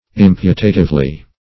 Meaning of imputatively. imputatively synonyms, pronunciation, spelling and more from Free Dictionary.
-- Im*put"a*tive*ly , adv.
imputatively.mp3